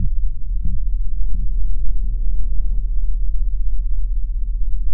持续低音
描述：持续低音，振荡低音。
标签： 频率 持续音 振荡器 LFO 低音 环境音 包络
声道立体声